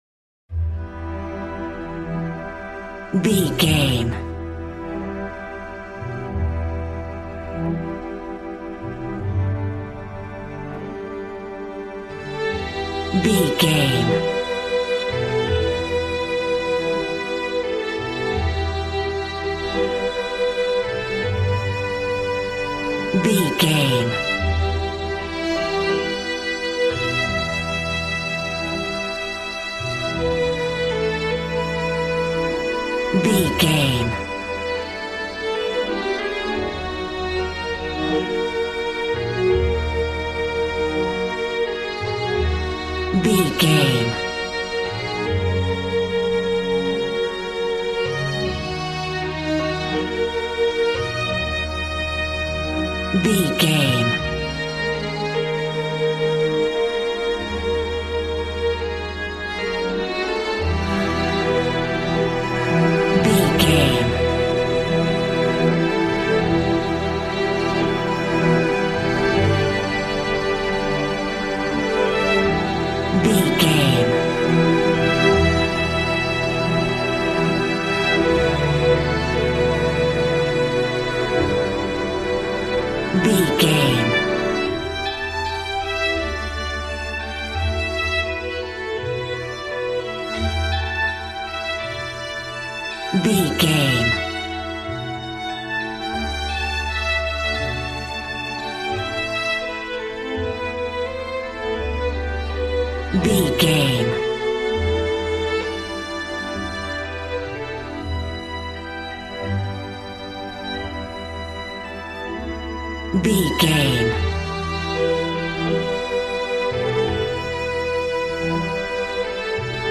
Ionian/Major
joyful
conga
80s